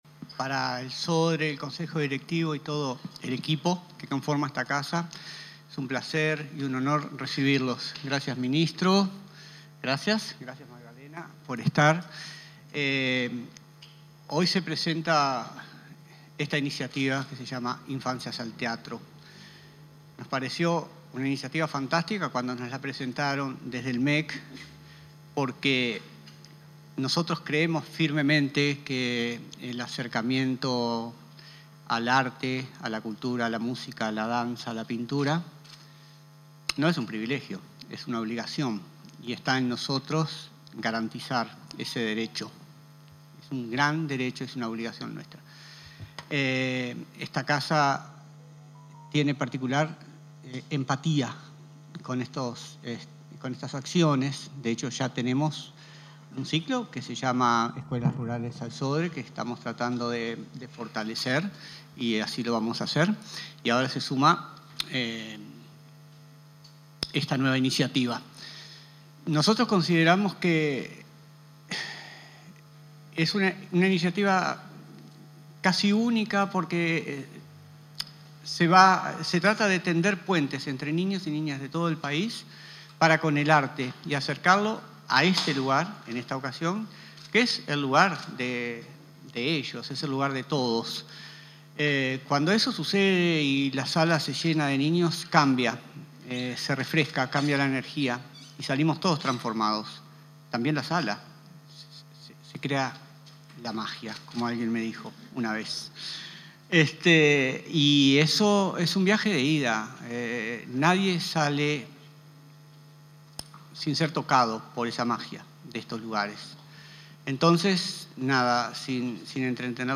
Palabras del ministro de Educación, José Mahía y del presidente del Sodre, Luis Pérez Aquino
El ministro de Educación y Cultura, José Mahía y el presidente del Sodre, Luis Pérez Aquino, presentaron la iniciativa Infancias al Teatro, en el